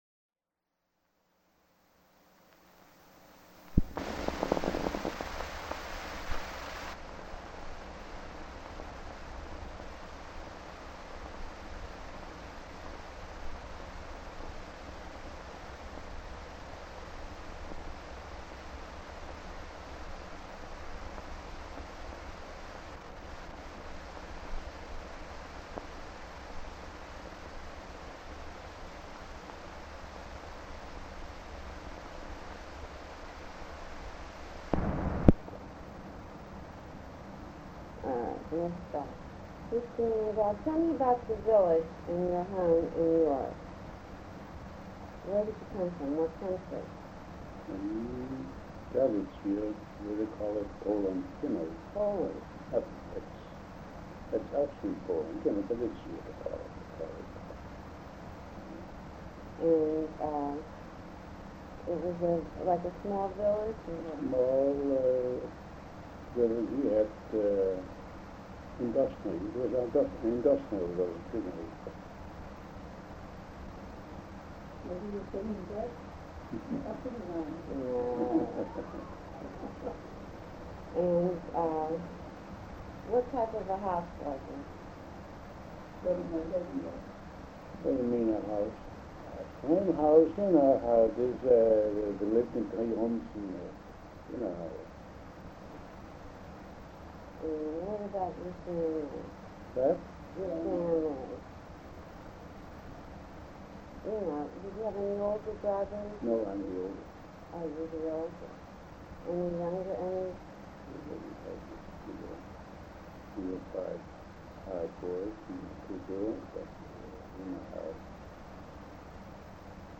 TAPE QUALITY POOR